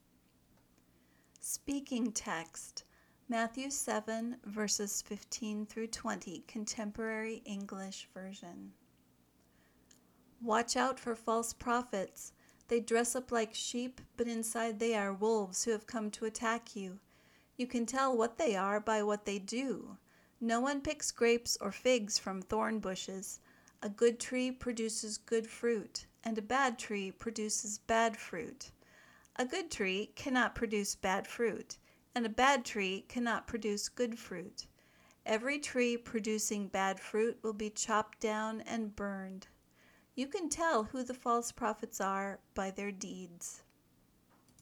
Try to imitate the speaker’s intonation, the rhythm of her speech, and the stresses she puts on words and syllables.
Listen for the “stop t” and “stop d” at the ends of words and try to duplicate the sound.